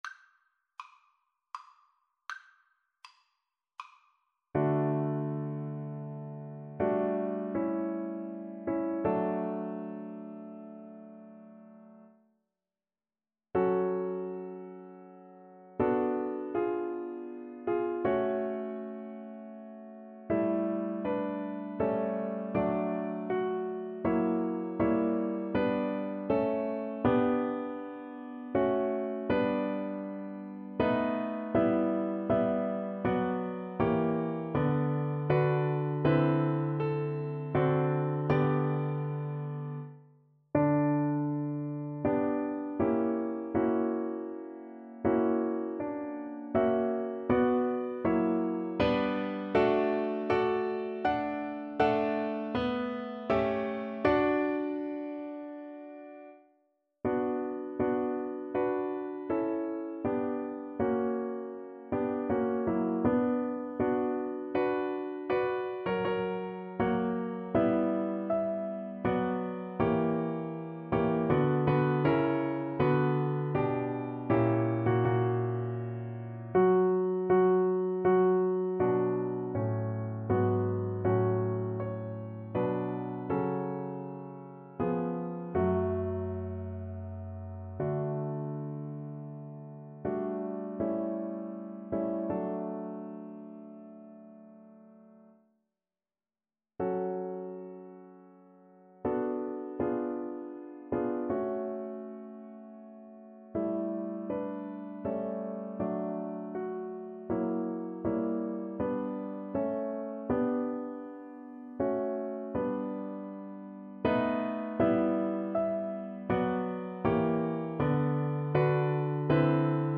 Play (or use space bar on your keyboard) Pause Music Playalong - Piano Accompaniment Playalong Band Accompaniment not yet available reset tempo print settings full screen
D major (Sounding Pitch) (View more D major Music for Viola )
3/4 (View more 3/4 Music)
~ = 80 Andante ma non lento